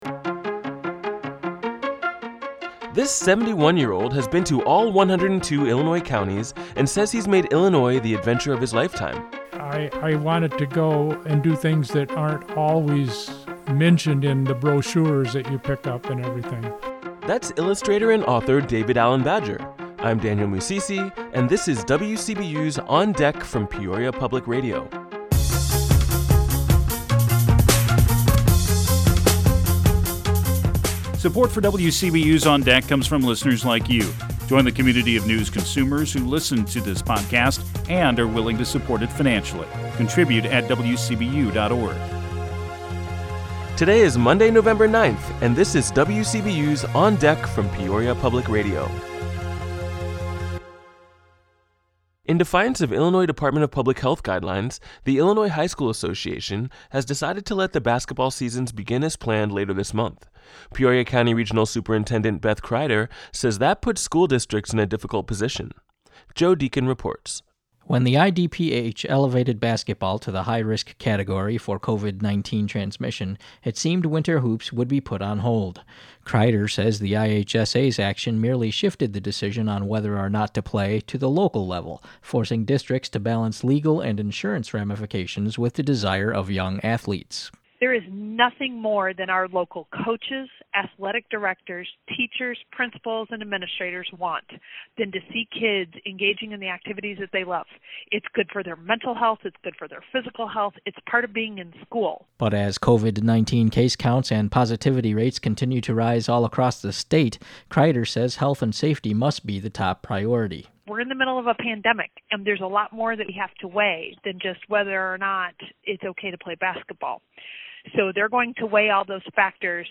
Our top story is about how in defiance of Illinois Department of Public Health guidlines, the Illinois High School Association has decided to let the basketball seasons begin as planned later this month. You'll hear from Peoria County Regional Superintendent Beth Crider, who says that puts school districts in a difficult position.